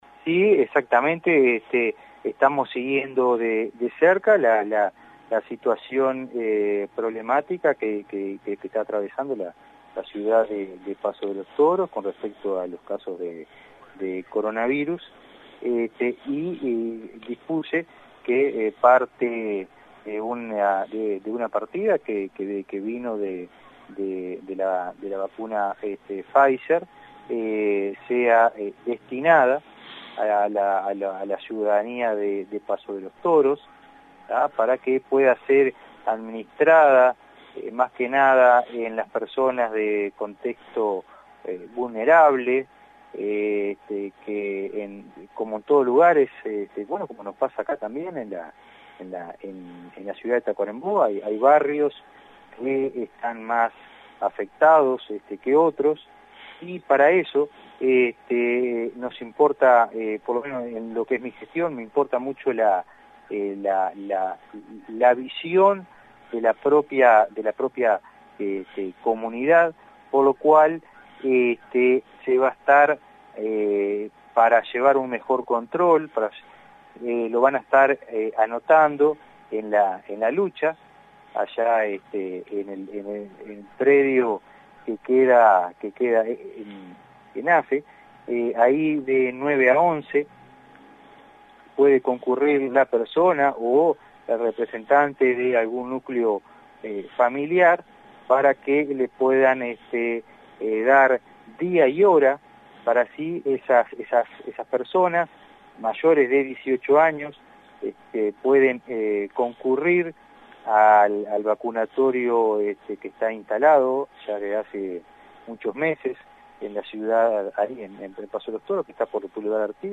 El Director Departamental de Salud, Dr. Juan Motta, informó a través de AM 1110 acerca de la llegada de dosis de vacunas contra Covid-19 Pfizer, dirigidas a personas del contexto más vulnerable, mayores de 18 años, de diferentes barrios de nuestra ciudad.